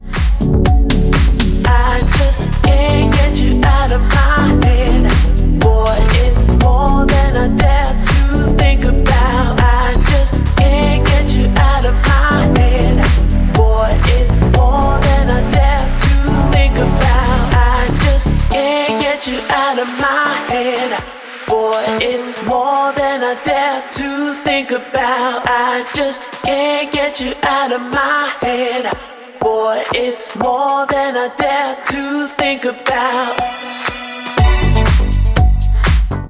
• Качество: 128, Stereo
ритмичные
громкие
deep house
красивая мелодия
чувственные